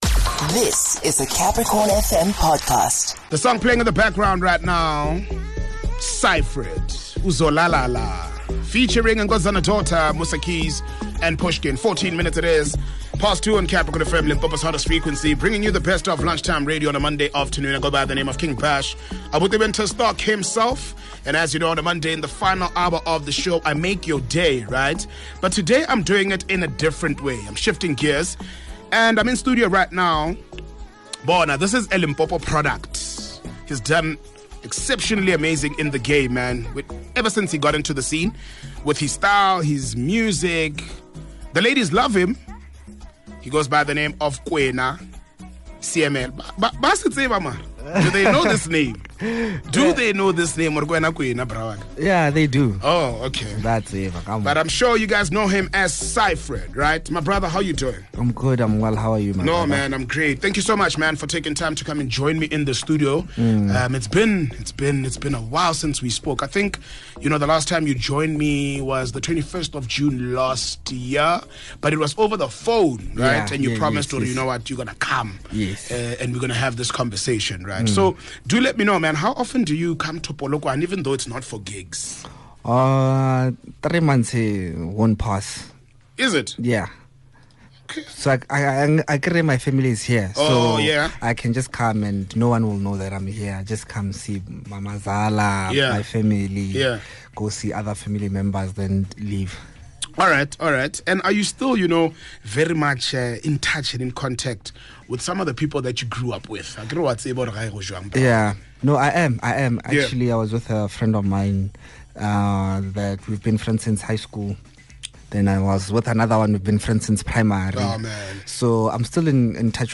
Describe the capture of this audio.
is joined in studio